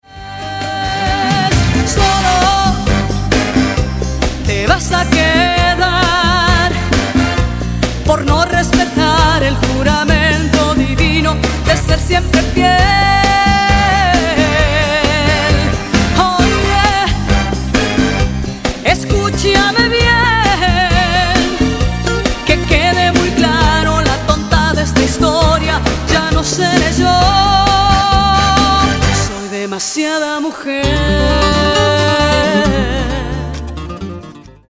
romantic and passionate songs